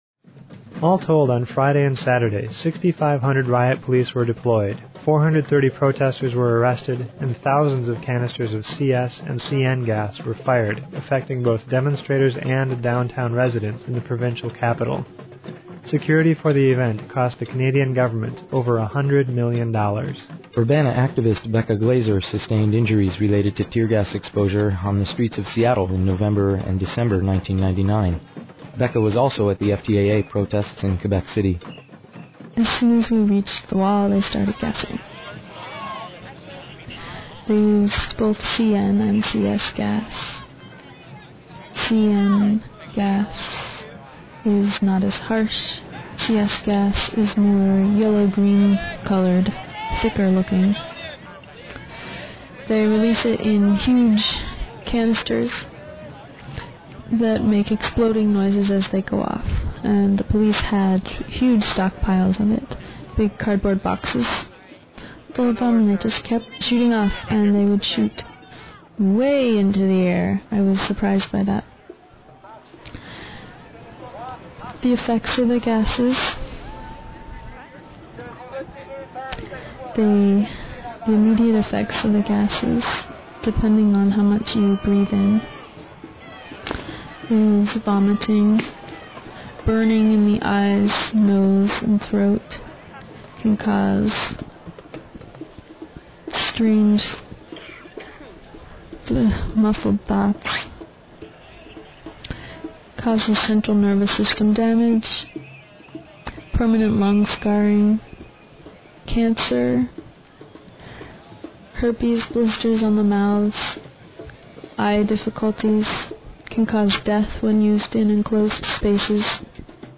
Just days after arriving home from Quebec City and the protests surrounding the Summit of the Americas, Urbana-Champaign Independent Media Center journalists compiled a half-hour document of their journey and experiences on the streets of Quebec City.
Artist/Provider U-C IMC Journalists